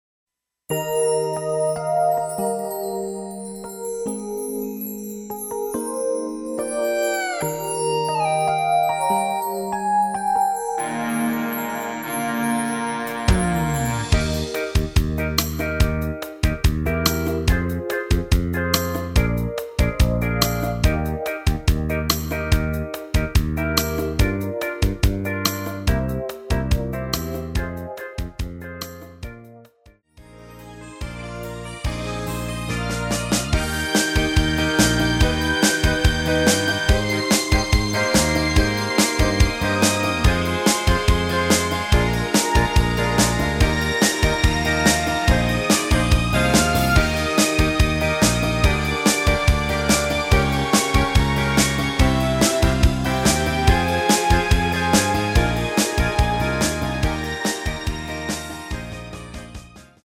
엔딩이 페이드 아웃이라 라이브 하시기 좋게 엔딩을 만들었습니다.
Eb
앞부분30초, 뒷부분30초씩 편집해서 올려 드리고 있습니다.